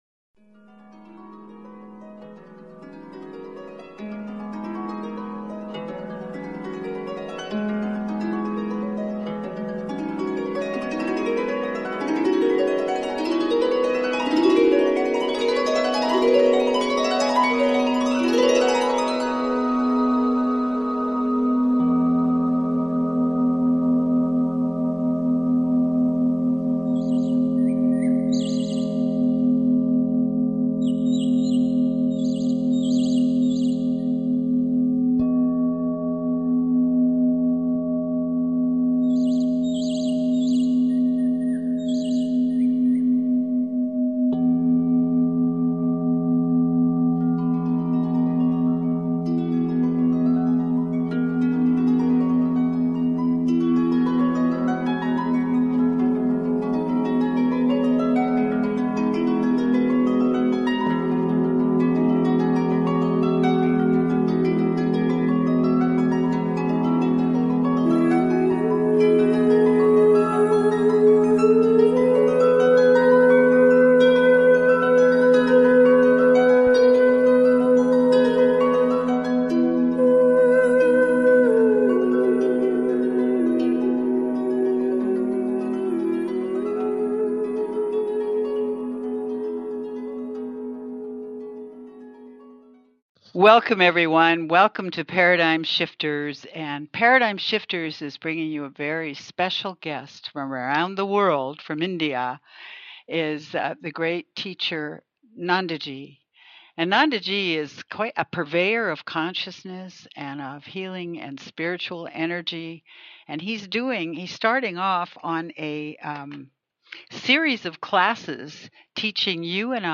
Talk Show Episode, Audio Podcast, Paradigm Shifters and Guest